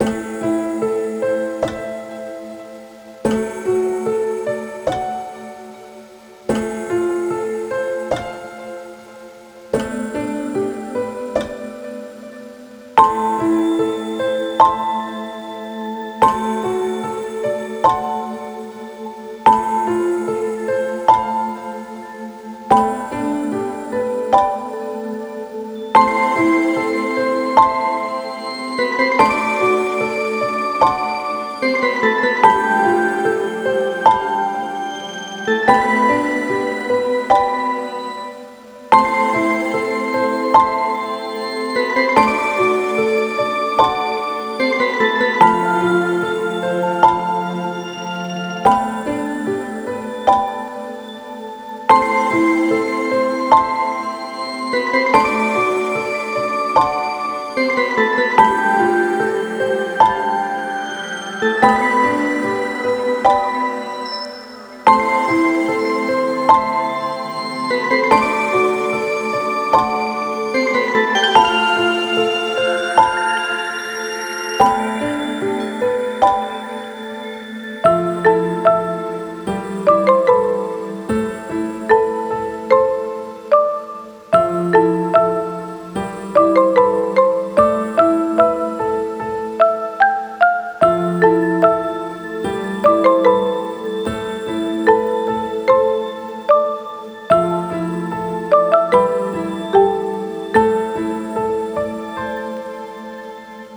暗い楽曲
【イメージ】幻、記憶のなかの風景 など